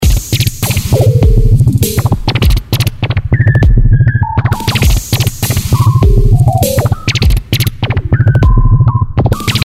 Dort habe ich einen Verzerrer, Modulated Filter, Frequency Shifter und Channel Compressor eingebaut.
Die Tonhöhe des Frequency Shifters habe ich mit Sample & Hold über die TransMod Engine moduliert, sodass sich ein variantenreiches, ständigen Veränderungen unterworfenes Piepsen ergibt.
… und nach der Transformation: